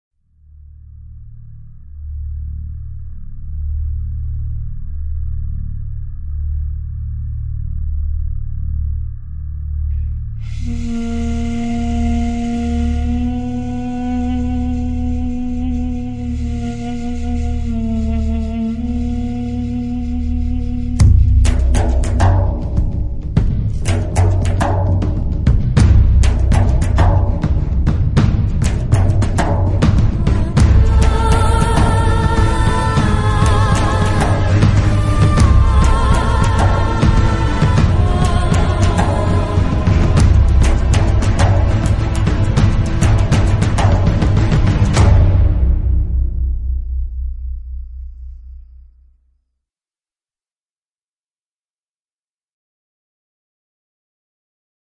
Low Quality